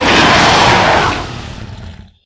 hurt_2.ogg